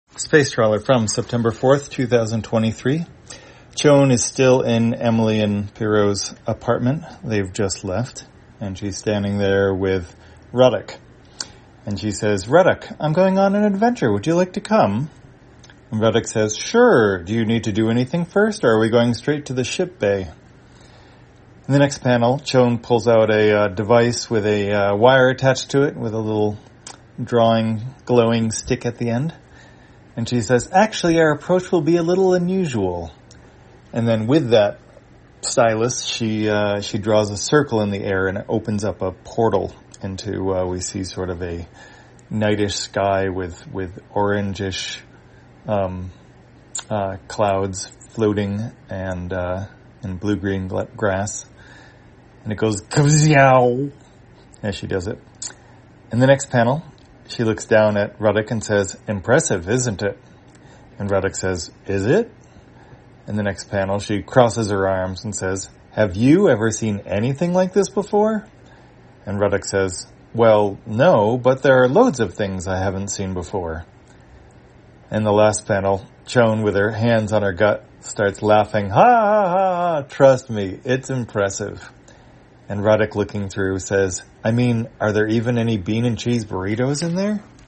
Spacetrawler, audio version For the blind or visually impaired, September 4, 2023.